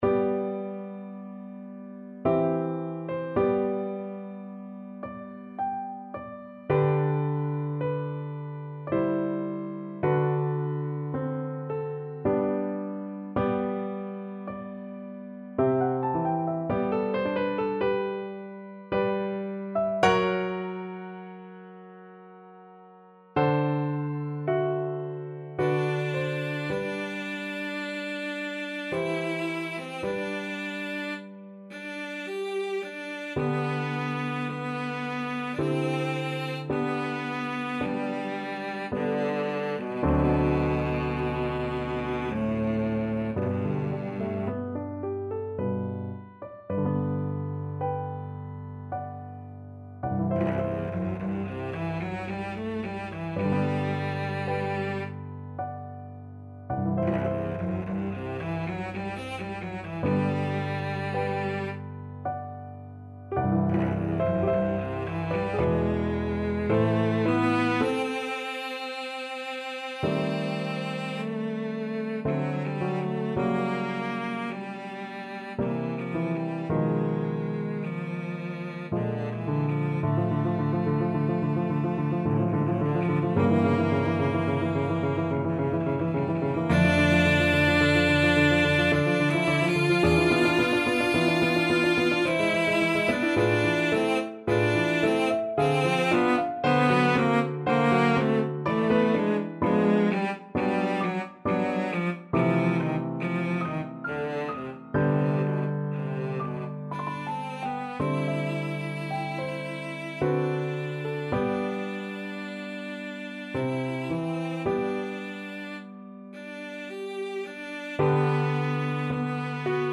Cello
3/4 (View more 3/4 Music)
G major (Sounding Pitch) (View more G major Music for Cello )
Adagio ma non troppo =108
Classical (View more Classical Cello Music)
dvorak_cello_concerto_2nd_mvt_VLC.mp3